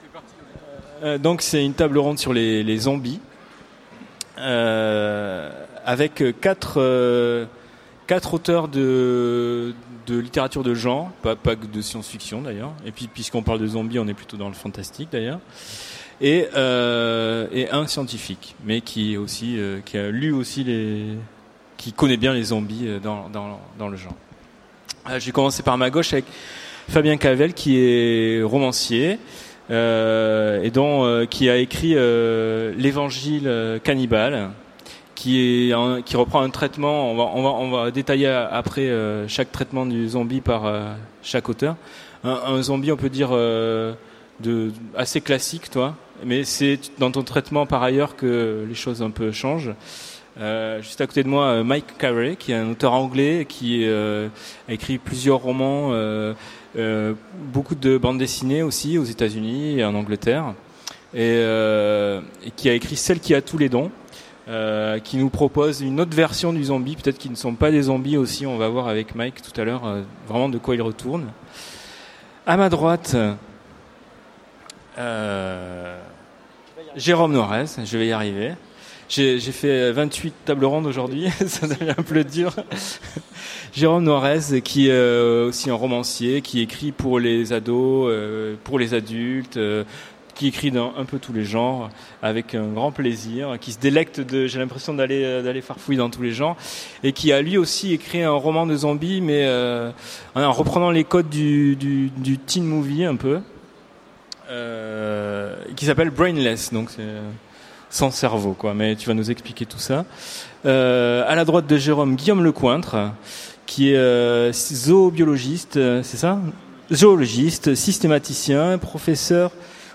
Utopiales 2015 : Conférence Zombification
Conférence